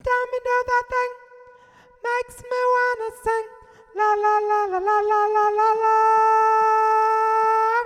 everytimevoxhi.wav